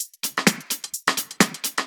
Index of /VEE/VEE Electro Loops 128 BPM
VEE Electro Loop 351.wav